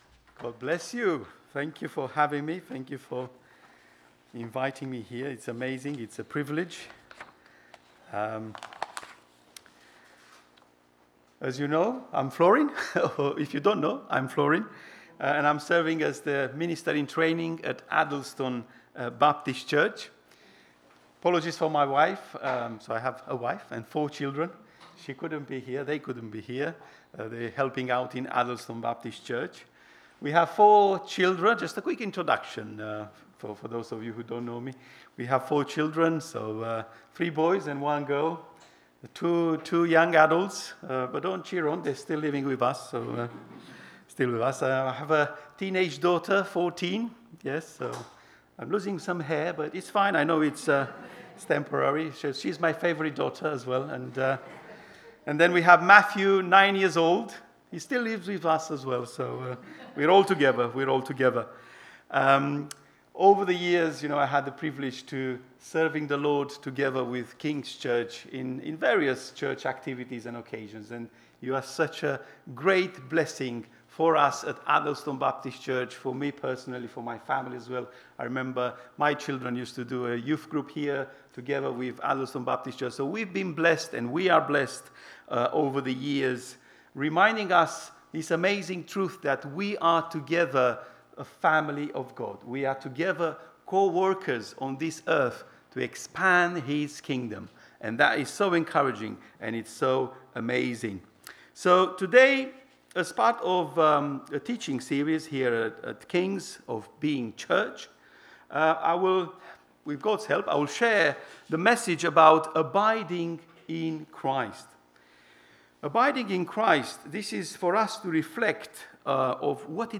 Church Service 08/06/2025
Weekly message from The King’s Church.